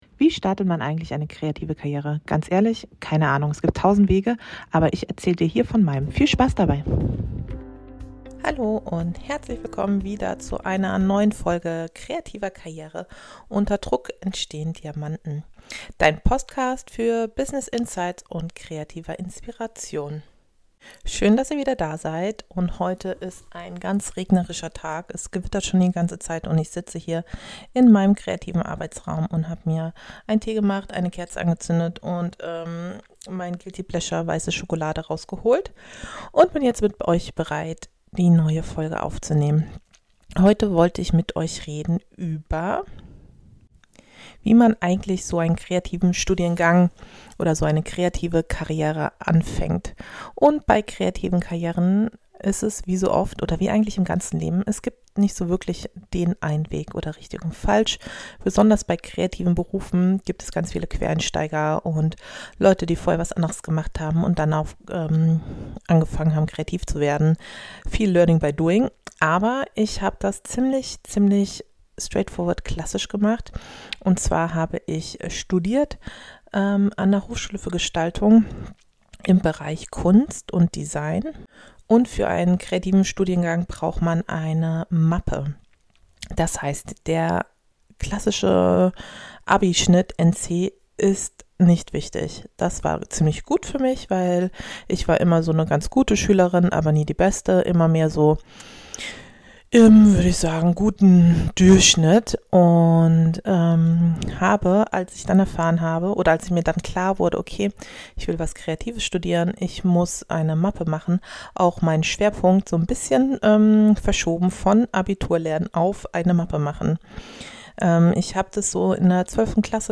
In meiner neuen Solo-Folge spreche ich darüber, wie ich überhaupt ins Tun gekommen bin. Wie ich meine Mappe aufgebaut habe, warum ich aufgehört habe auf den „perfekten Moment“ zu warten – und was passiert, wenn man sich einfach mal traut.